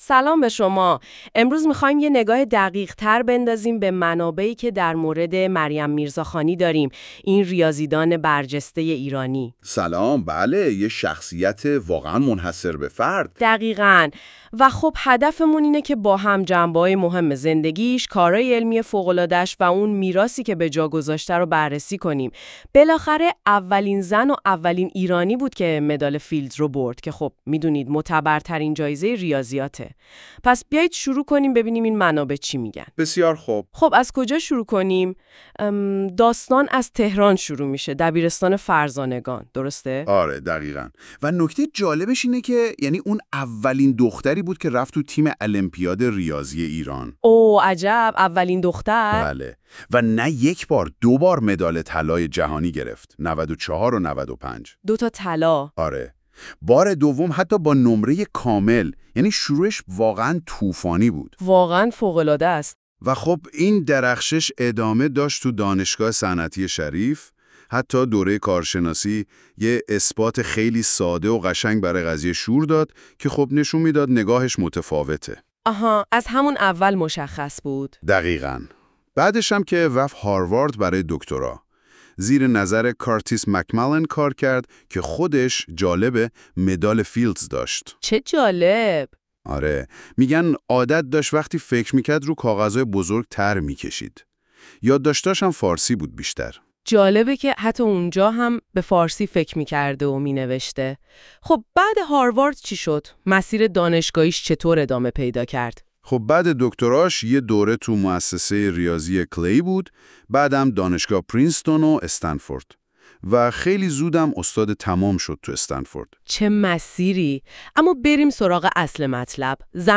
این فایل صوتی با استفاده از هوش مصنوعی تولید شده است تا تجربه‌ای بهتر و کاربرپسندتر برای شما فراهم شود.